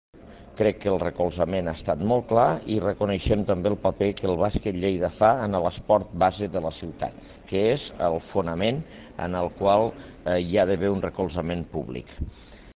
tall-de-veu-angel-ros-acord-lleida-basquet